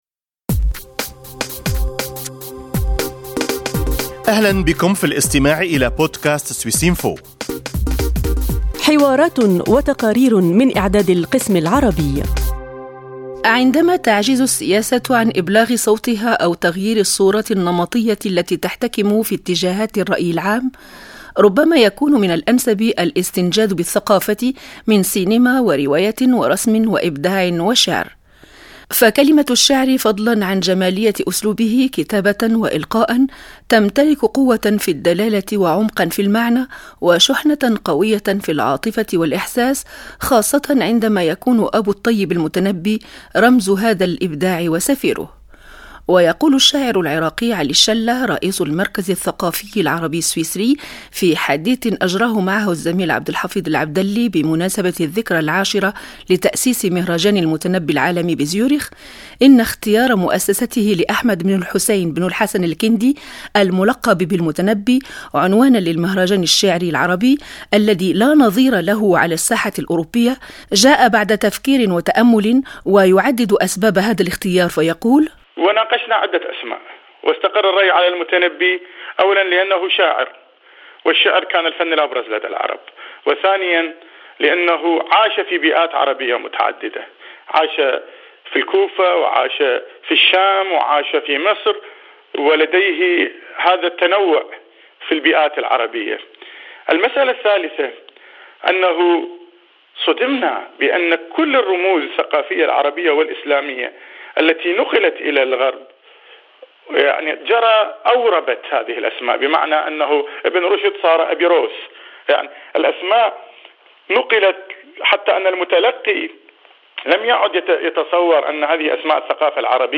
حديث